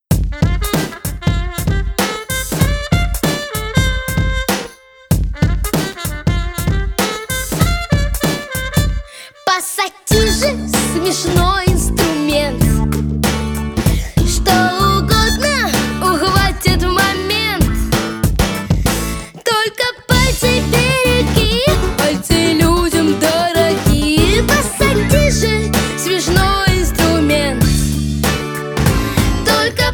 Children's Music